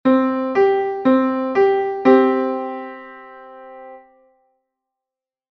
A perfect fifth consists of three whole-steps and one half-step.
perfect-5.mp3